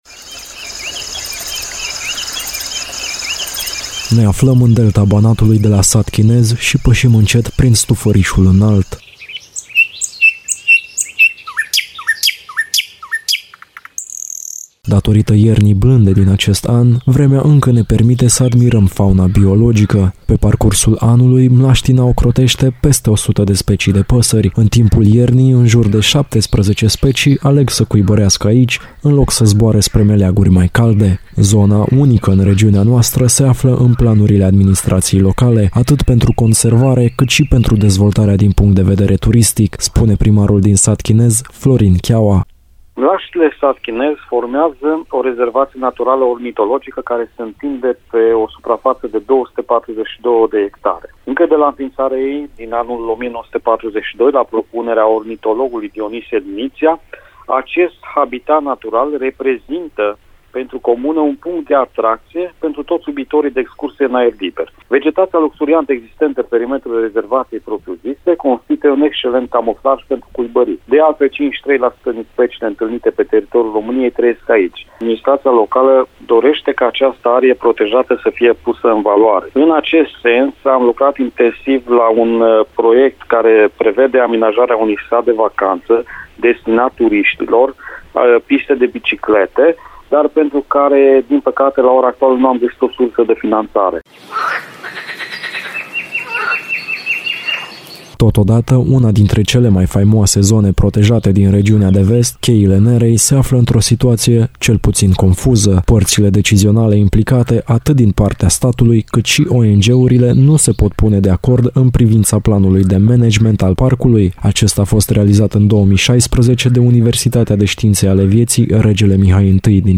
REPORTAJ/ Soarta rezervațiilor și parcurilor naturale din Banat: Delta Banatului și Cheile Nerei